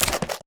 creaking_step1.ogg